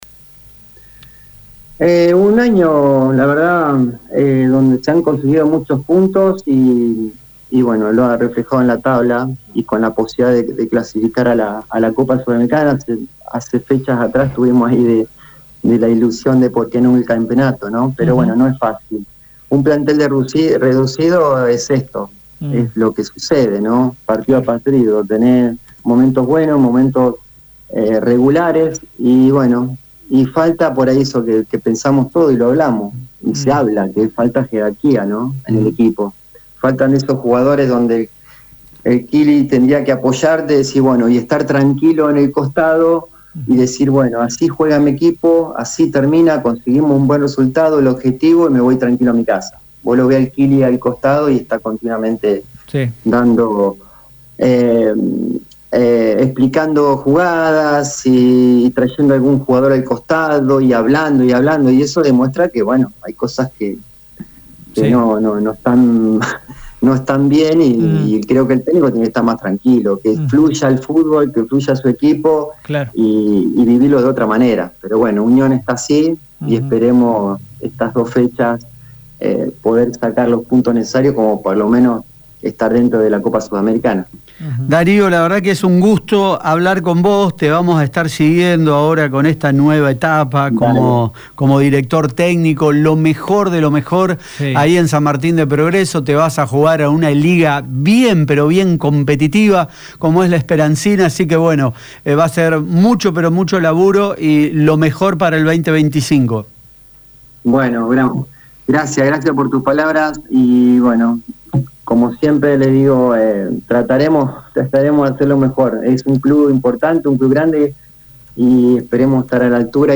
En charla con Radio EME Deportivo